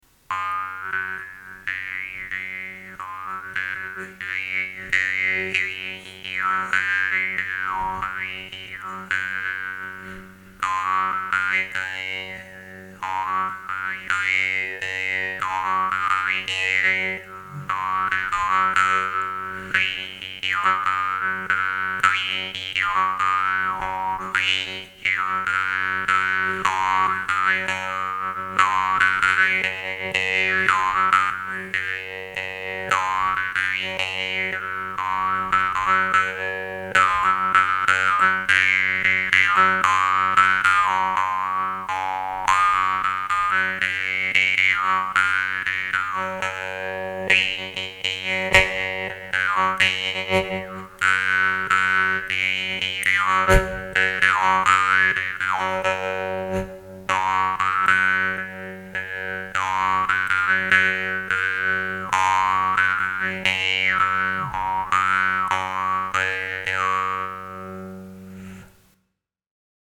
Мягкий язычок, точно выверенный зазор между язычком и деками, множество обертонов, красивый мелодичный звук, чувствительность к дыханию и к любого рода артикуляции - все это будет интересно и начинающим и профессионалам.
nepal_oak1.mp3